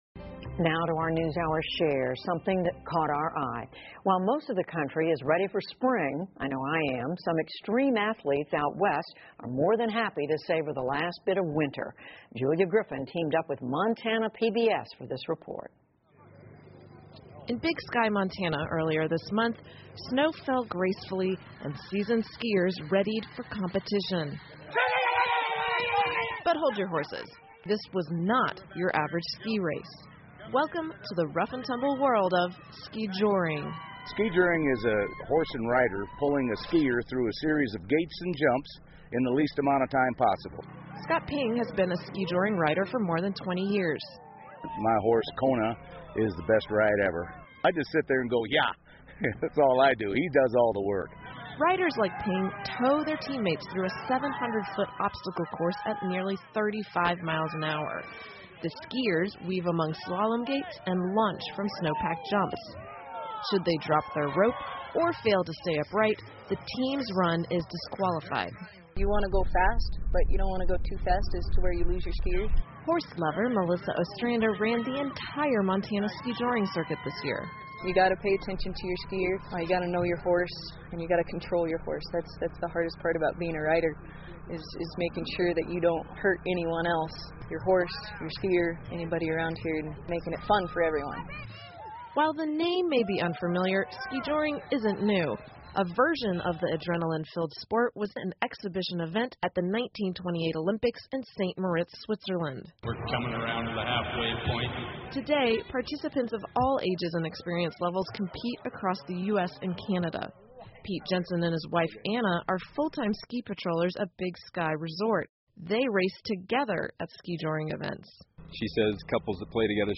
PBS高端访谈:滑雪跳与竞技结合的一种极限运动 听力文件下载—在线英语听力室